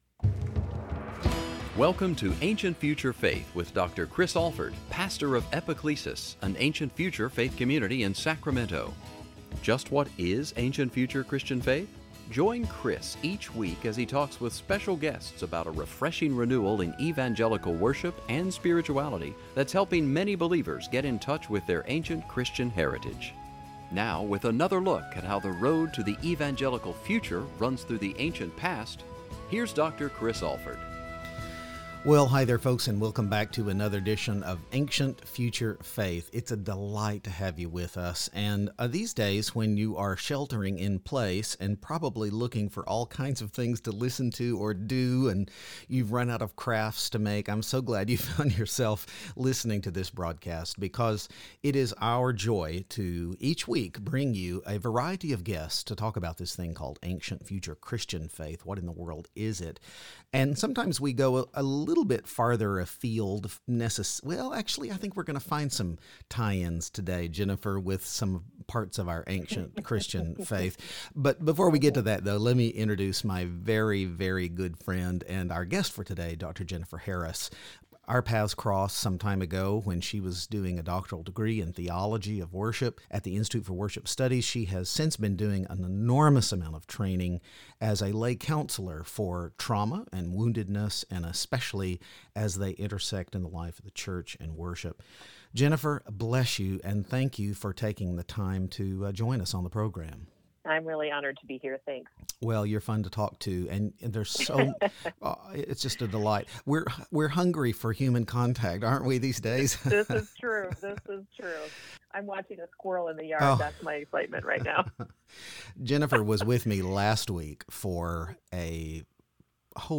Are you suffering, or are there parts of you that are enslaved in "a distant land"? Join us for this thoughtful, relevant conversation.